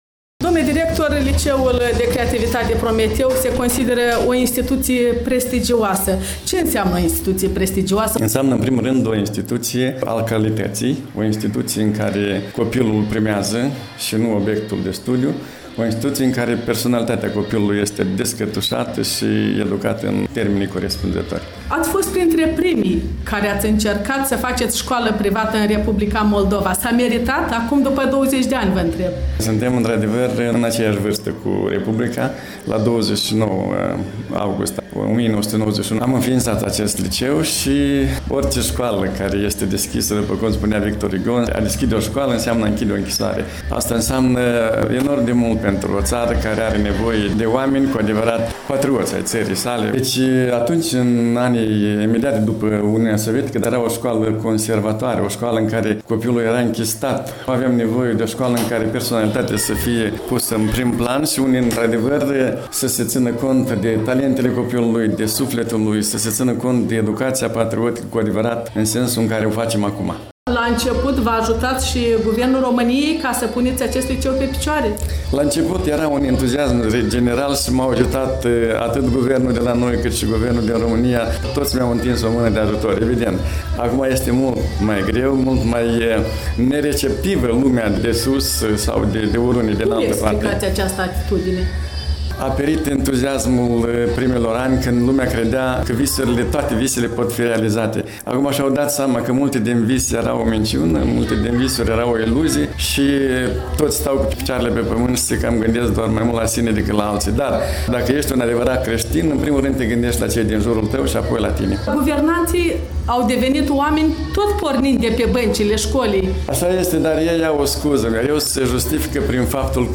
Un interviu